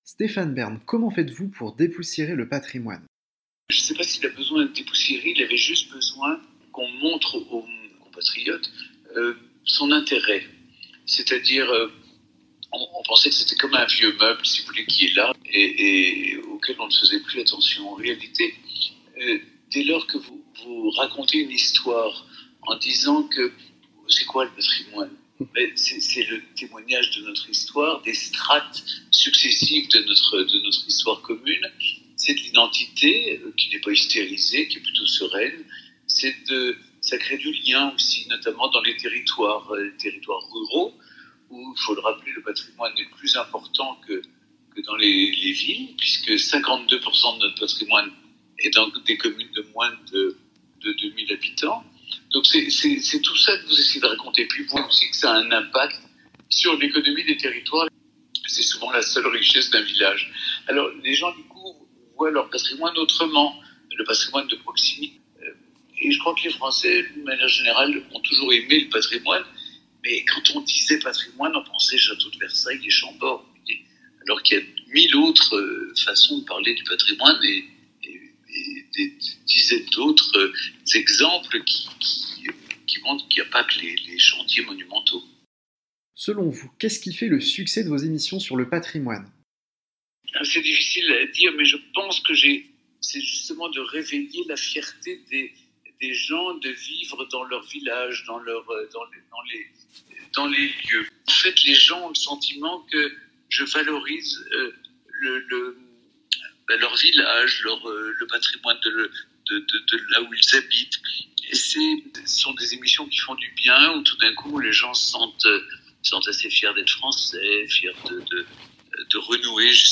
L’animateur de Secrets d’Histoire et du Village préféré des Français, a accordé une interview à PHRases.